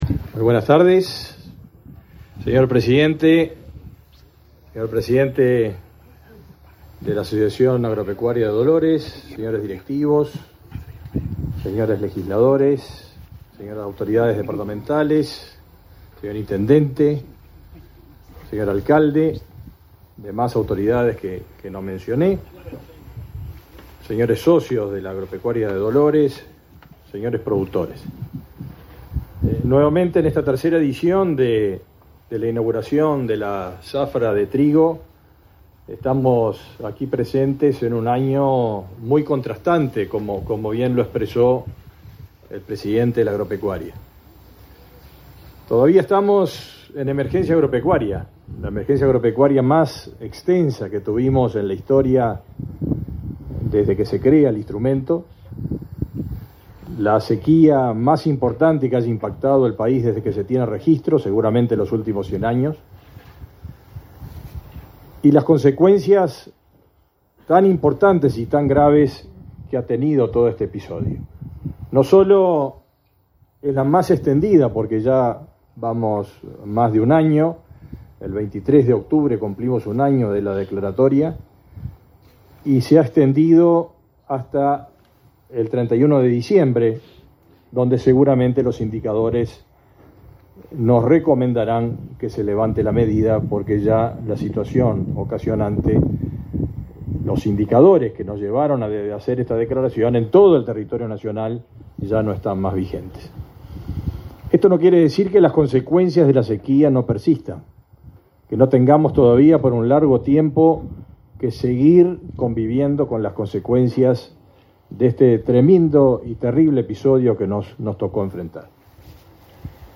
Palabras del ministro de Ganadería, Agricultura y Pesca, Fernando Mattos
Palabras del ministro de Ganadería, Agricultura y Pesca, Fernando Mattos 17/11/2023 Compartir Facebook X Copiar enlace WhatsApp LinkedIn Este 17 de noviembre se realizó el acto de inauguración de la cosecha de trigo, en la ciudad de Dolores, en el departamento de Soriano, con la presencia del presidente de la República, Luis Lacalle Pou. En el acto disertó el ministro de Ganadería Agricultura y Pesca, Fernando Mattos.